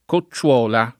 cocciuola [ ko ©©U0 la ]